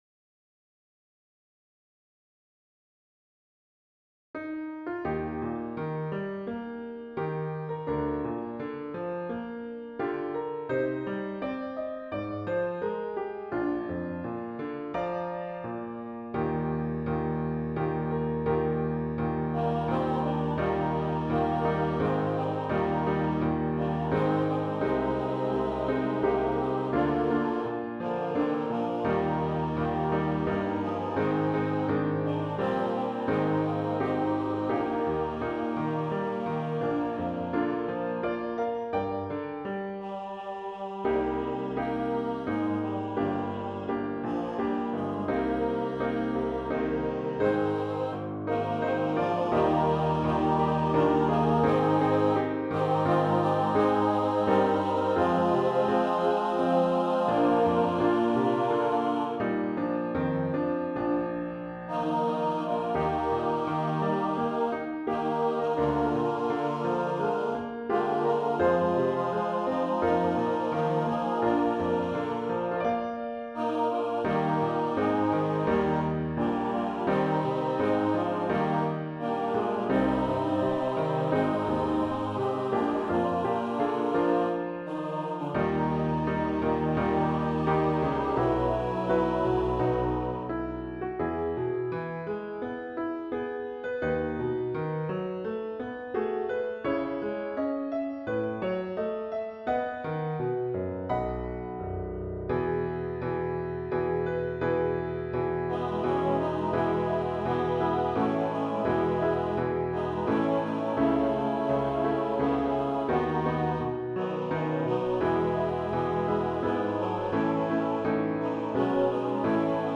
Christian, Gospel, Sacred.